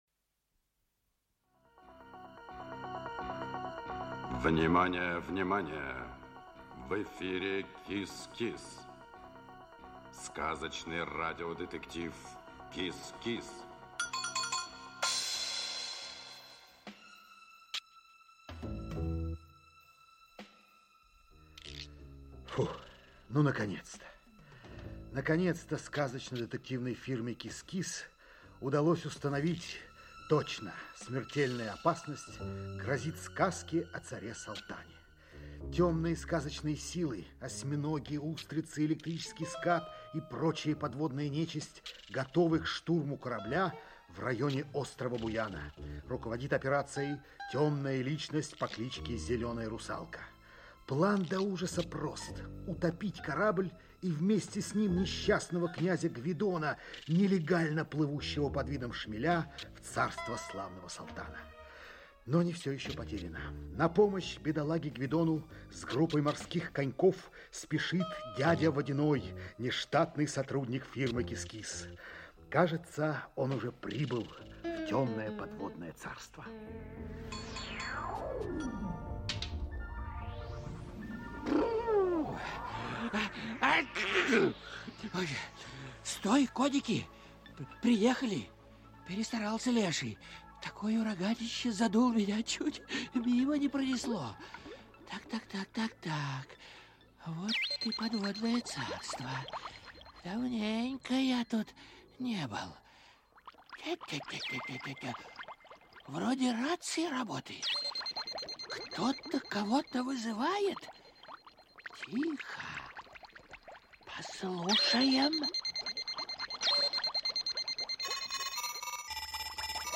Аудиокнига КИС-КИС. Дело № 2. "Операция "Шмель". Часть 5 | Библиотека аудиокниг
Часть 5 Автор Зоя Чернышева Читает аудиокнигу Александр Леньков.